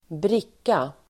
Uttal: [²br'ik:a]